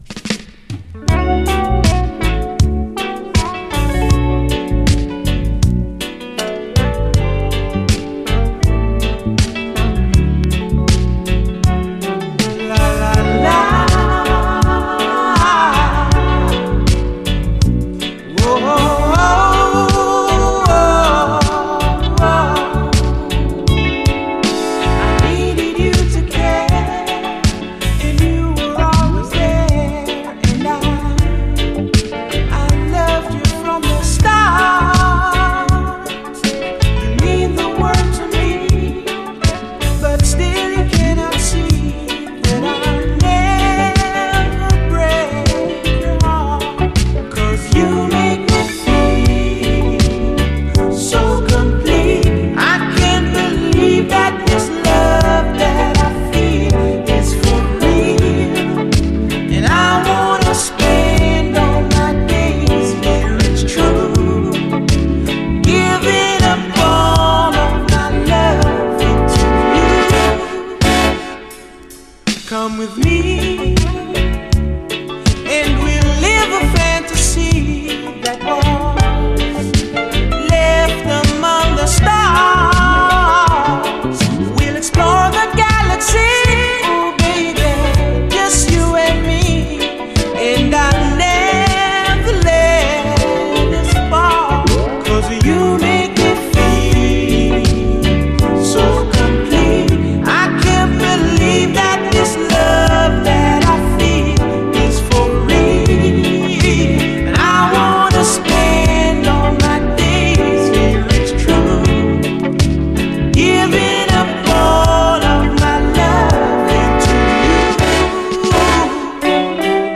REGGAE
盤表面的なスレありますがプレイは概ね良好。試聴ファイルはこの盤からの録音です
聴けば納得、トロけるようにロマンティックでアーバン。